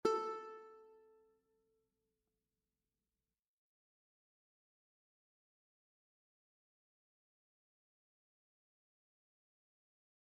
UKELELE CORDA 4,3,2,1 | Flashcards
La3 (audio/mpeg)